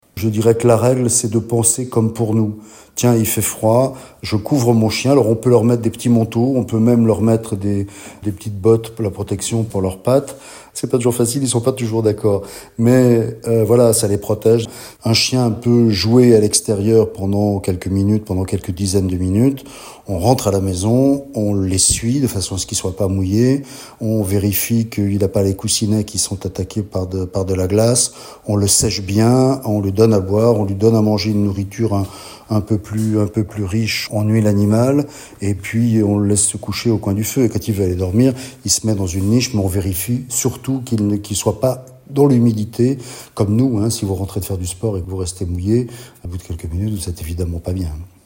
était à notre antenne pour donner quelques conseils sur l'attitude à adopter.